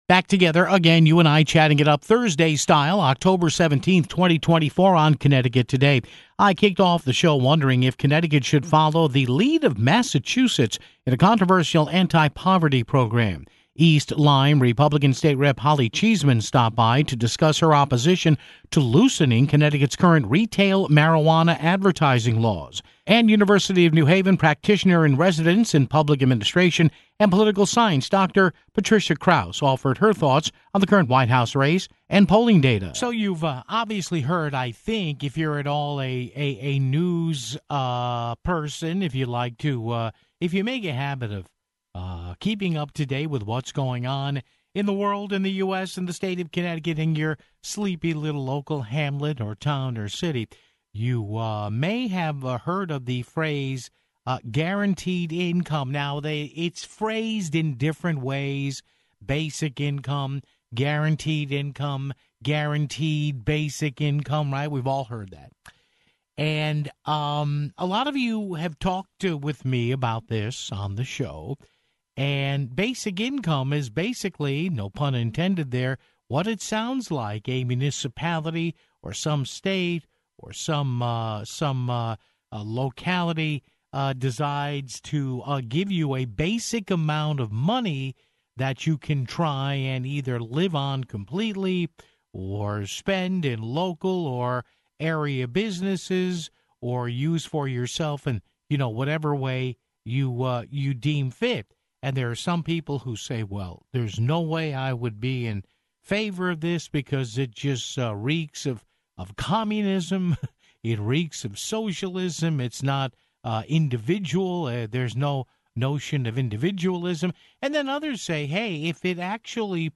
East Lyme GOP State Rep. Holly Cheeseman discussed her opposition to loosening Connecticut's current retail marijuana advertising laws (07:42).